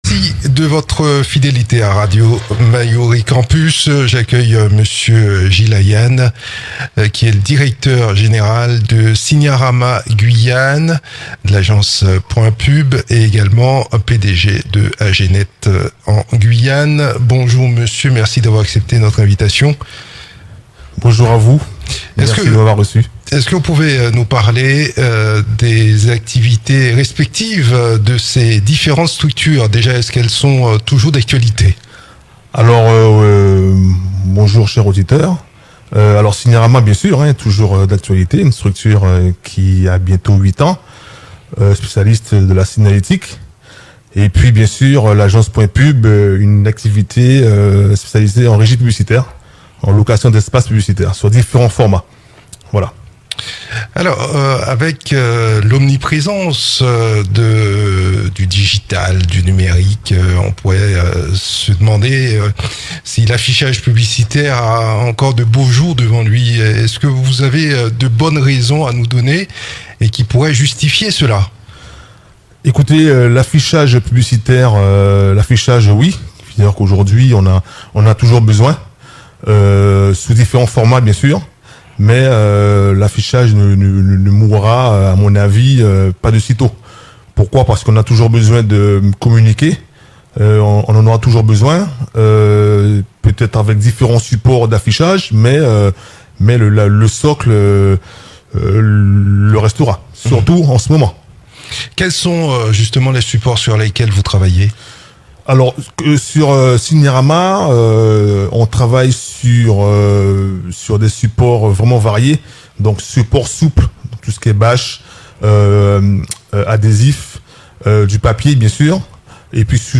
Dans le cadre de l'émission "Info Campus" sur Radio Mayouri Campus, diffusée du lundi au vendredi à 8h30 puis rediffusée à 10h10, 13h10 et 19h10.